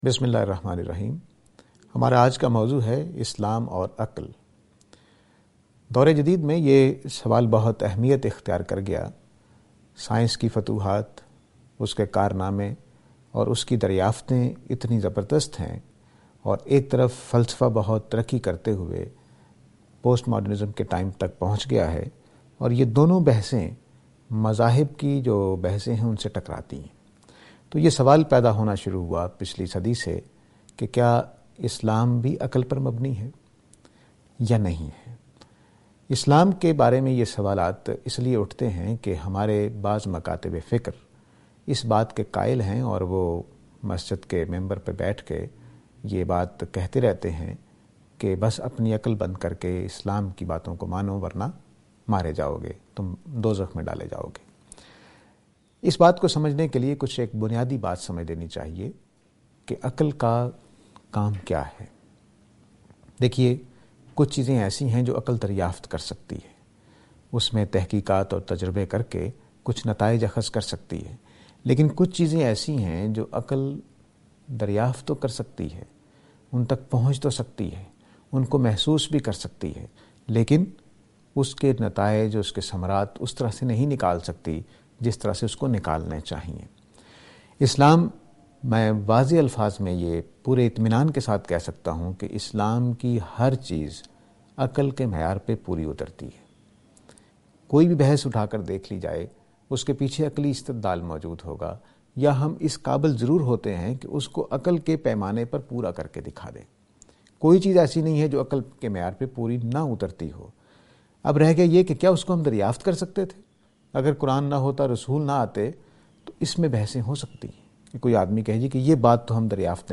This lecture is and attempt to answer the question "Islam and intellect".